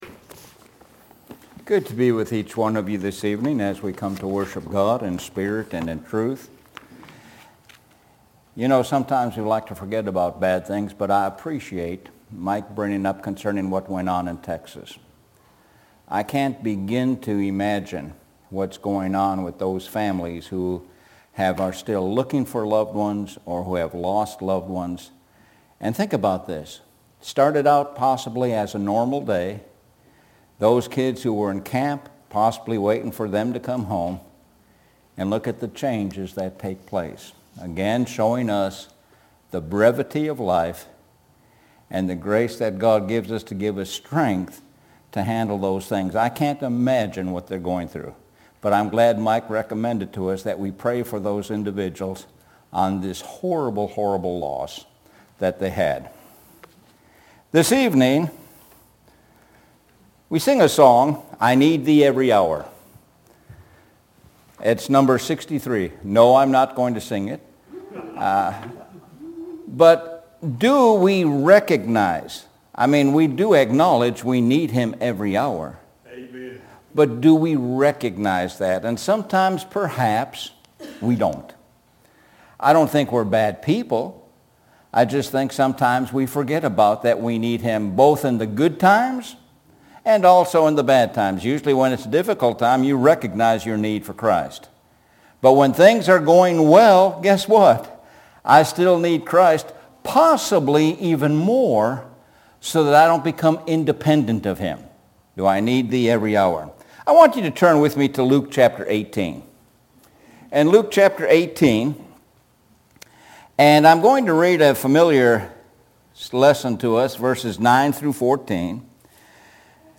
Sun PM Sermon